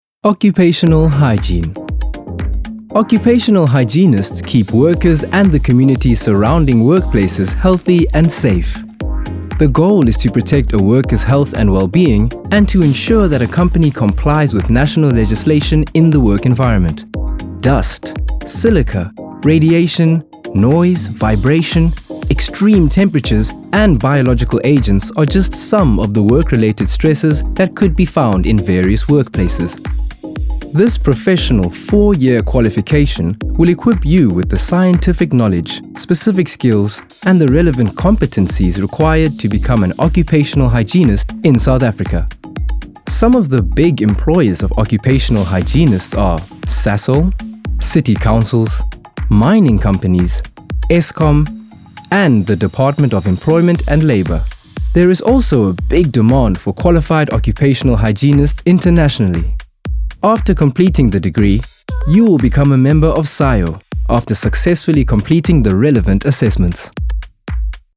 South Africa
energetic, playful, upbeat, youthful
My demo reels